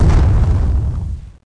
bombexplosionfar.mp3